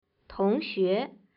口音有声数据
口音（男声）